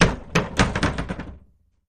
Cellar Door Open With More Bounces